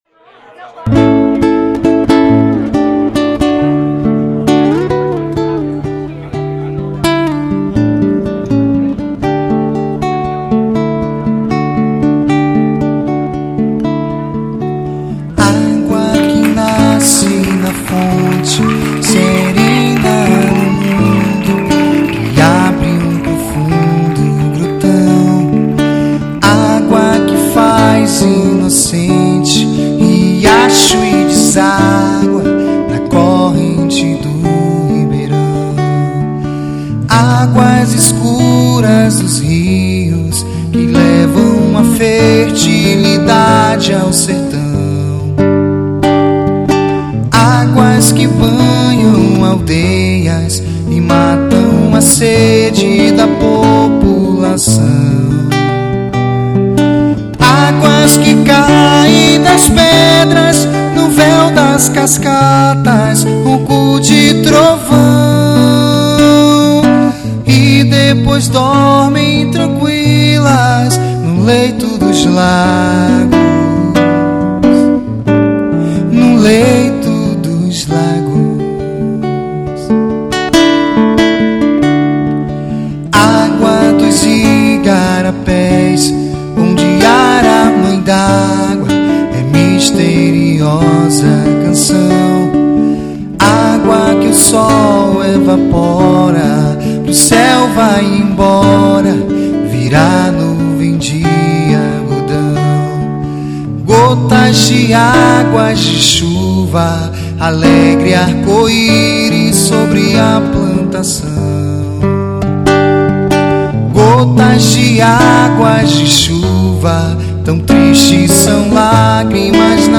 MPB.